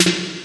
Free MP3 Roland TR606 - Snare drums 2
Snare - Roland TR 28